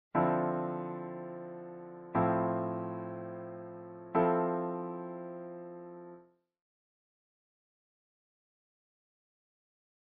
These 9th chord voicings we're examining here are perfect for just such an approach to playing changes in the band. Please examine these inversion voicings.